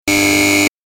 Wrong Buzzer Sound Effect
A loud, unpleasant warning sound, commonly used in games or quizzes to indicate an error or incorrect answer. Alarm buzzer or out of, time.
Wrong-buzzer-sound-effect.mp3